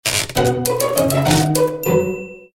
• Качество: 129, Stereo
Прикольное смс от модели Iphone 4